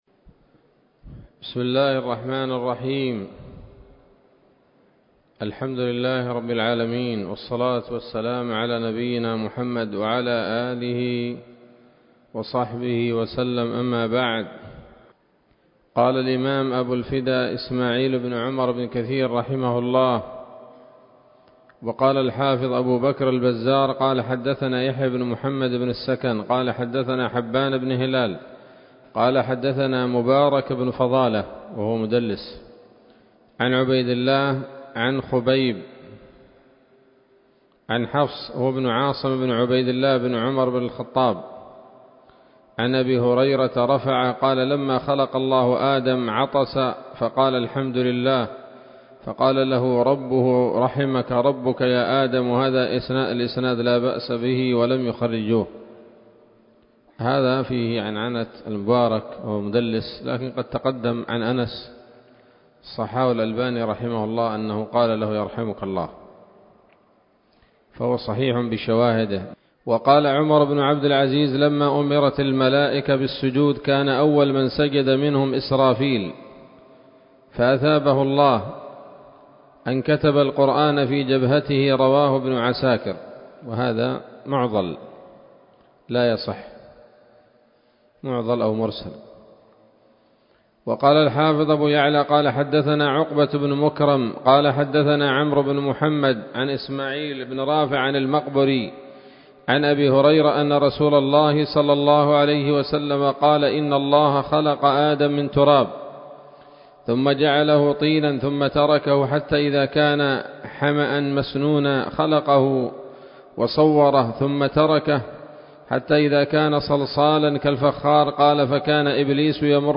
الدرس الحادي عشر من قصص الأنبياء لابن كثير رحمه الله تعالى